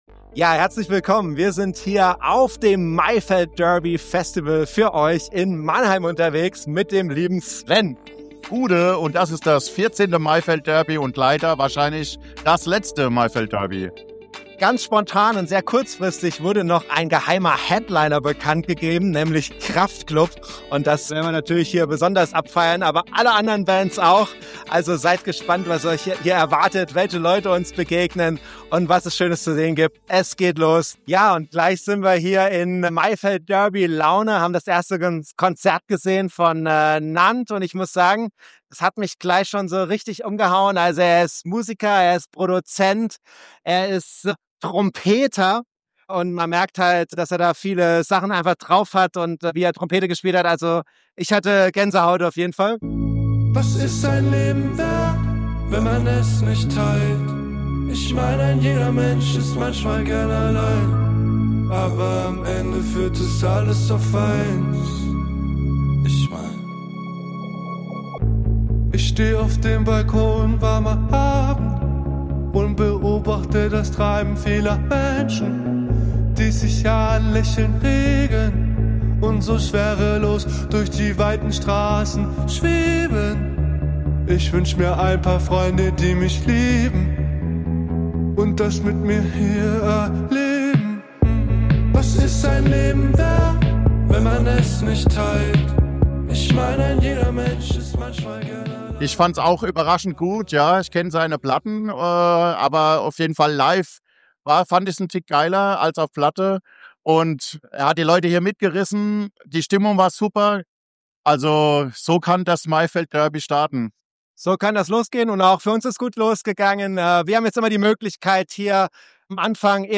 Maifeld Derby 2025 – Festivalbericht vom Maimarktgelände Mannheim.